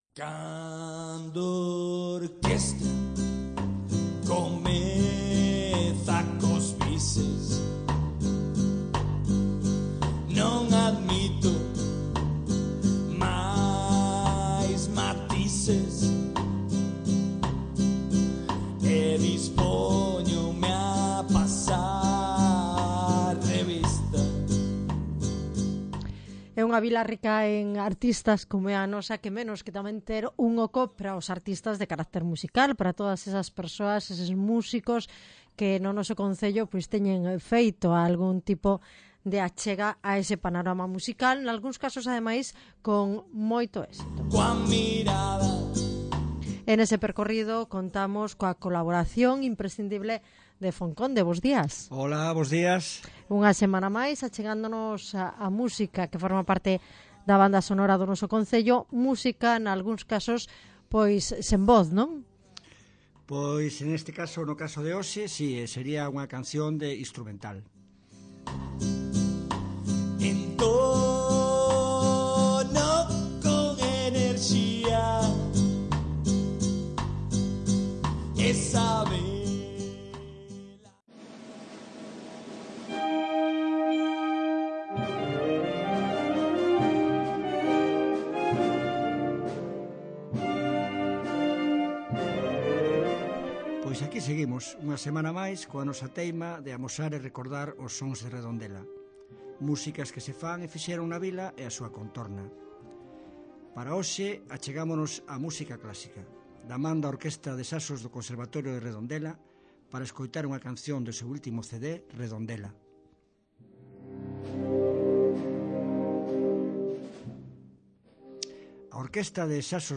Son de Redondela: Orquestra de Saxos